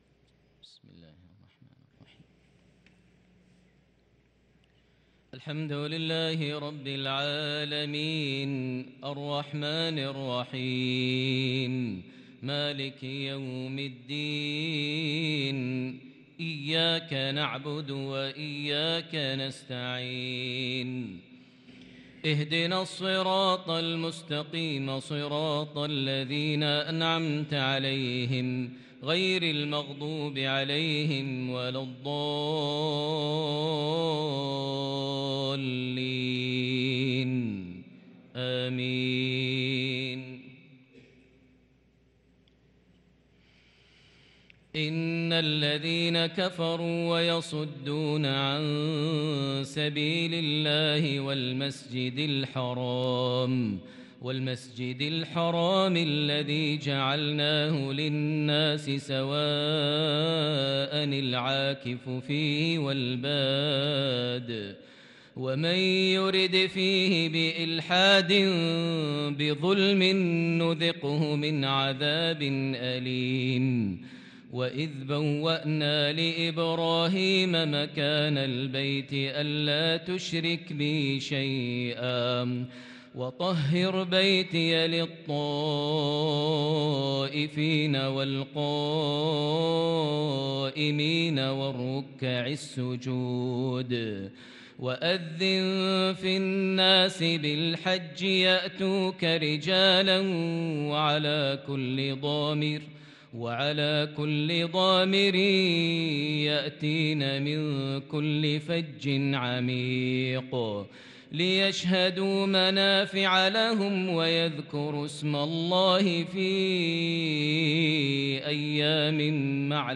صلاة العشاء للقارئ ماهر المعيقلي 2 ذو الحجة 1443 هـ
تِلَاوَات الْحَرَمَيْن .